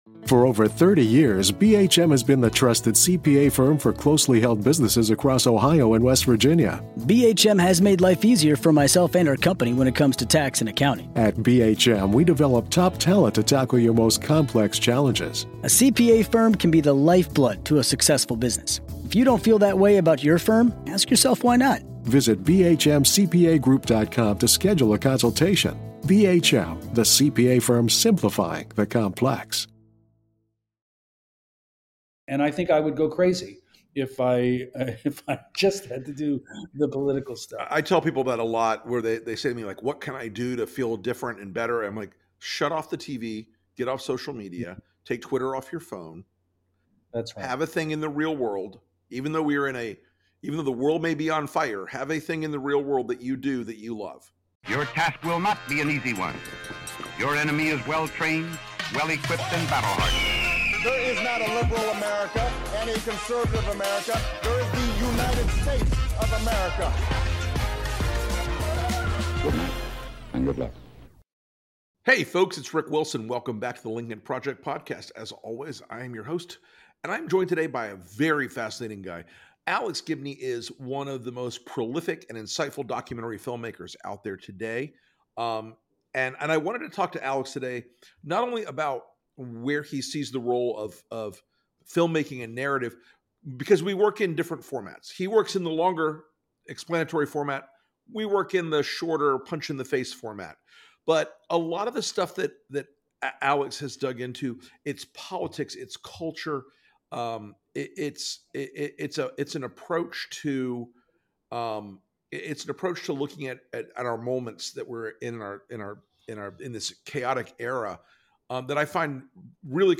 Prolific and multi-award-winning documentarian Alex Gibney, who's responsible for the most impactful documentaries of our time -- i.e., Going Clear: Scientology and the Prison of Belief, The Inventor: Out for Blood in Silicon Valley, Totally Under Control, and MANY more --sits down with Rick Wilson to discuss his portfolio of work and how it reflects on the American character.